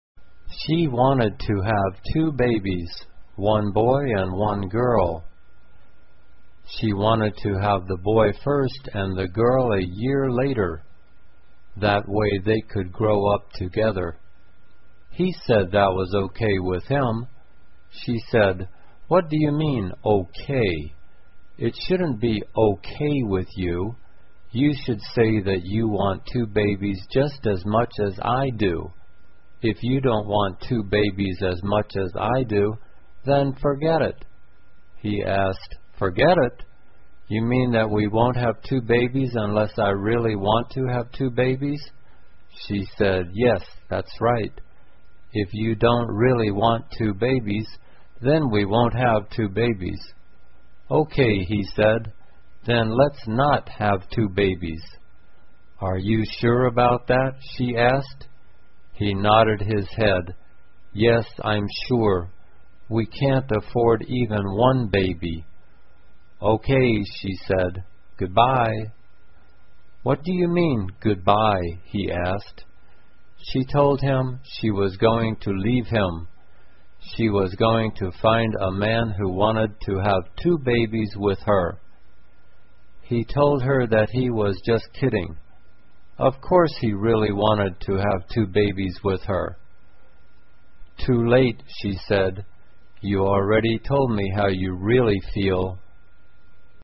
慢速英语短文听力 两个孩子 听力文件下载—在线英语听力室